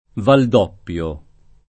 [ vald 0 pp L o ]